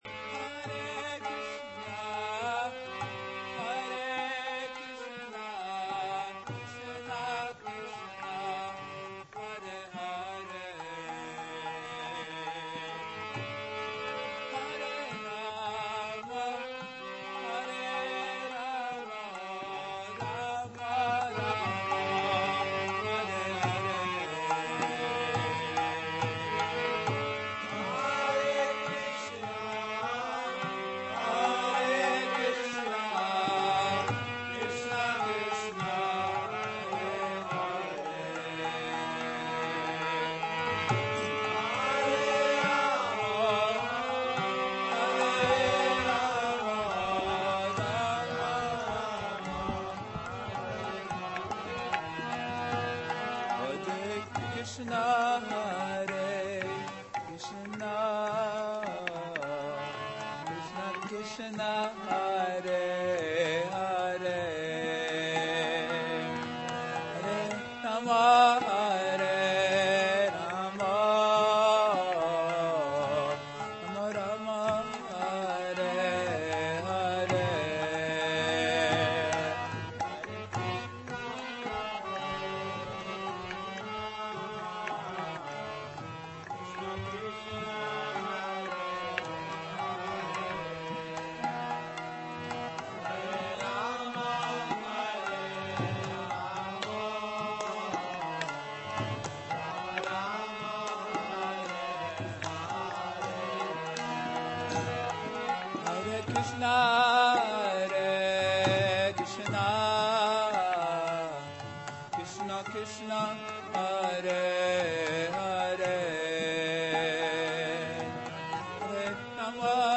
Hare Krsna Kirtana